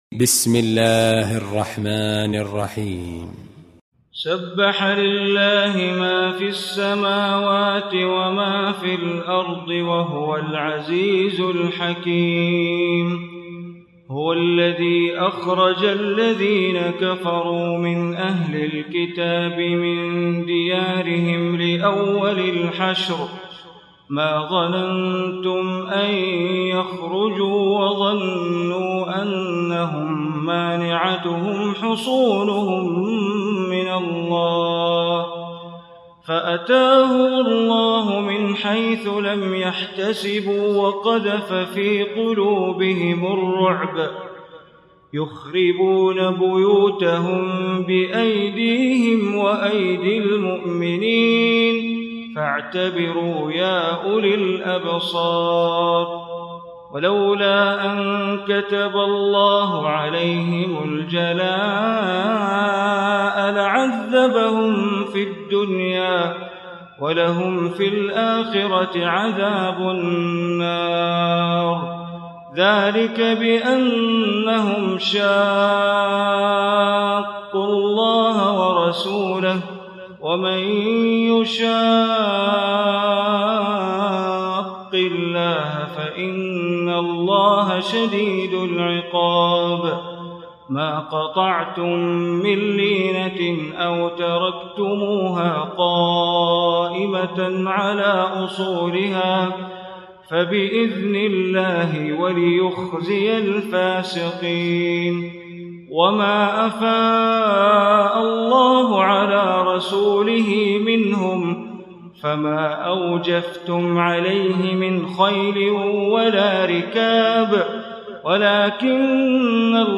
Surah Al-Hashr Recitation by Sheikh Bandar Baleela
Surah Al-Hashr, listen online mp3 tilawat / recitation in Arabic recited by Imam e Kaaba Sheikh Bandar Baleela.